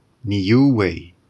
/niːˈjuːeɪ/(info), niueànisch Niuē; àlter Nàmma Savage Island, „wìlda Ìnsla“) ìsch a àbglaaganer Ìnselschtààt ìm Süüdpàzifik ìn dr Neecha vu Tonga, äbb 2.400 km nordeschtlig vu Näiseelànd.